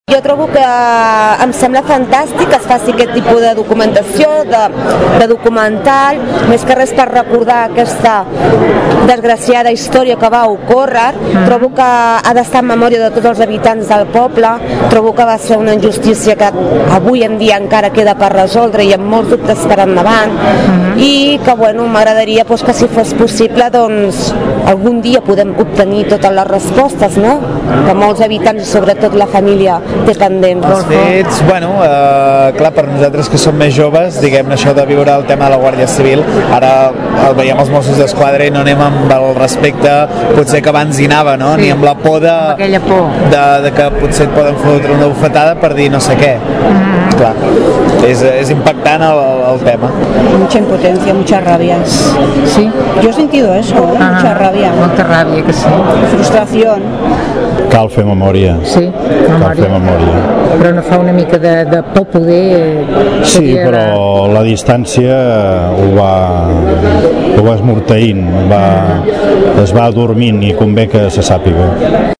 El programa Tordera en Directe d’avui recull diverses opinions del públic que aquest passat cap de setmana ha assistit a la projecció del documental. Aquestes són algunes de les impressions de la gent, al sortir de la sala.